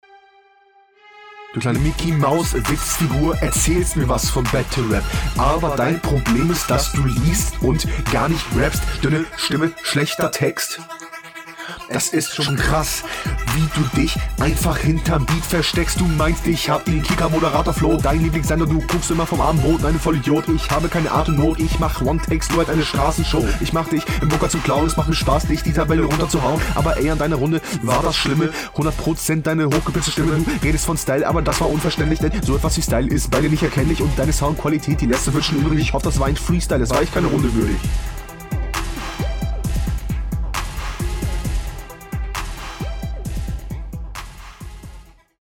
den einstieg habt ihr aber auf dem beat beide vercheckt.